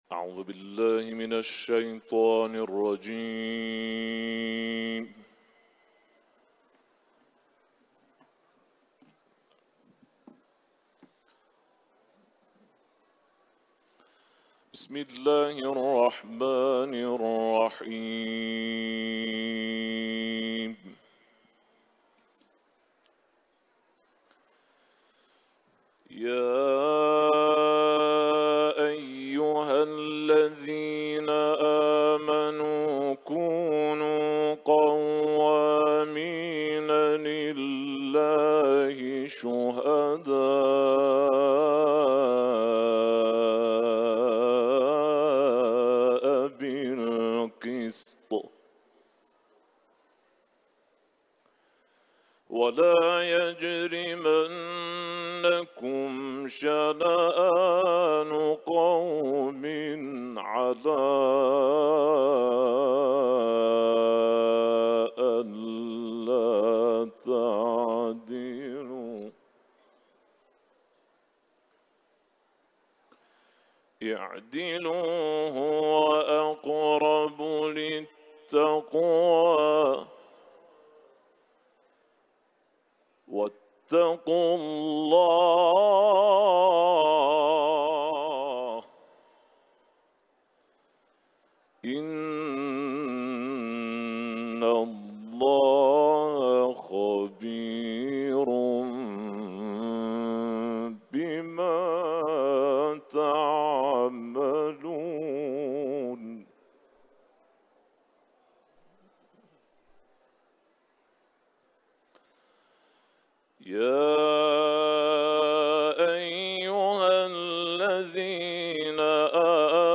Etiketler: Kuran tilaveti ، İranlı kâri ، Maide sûresi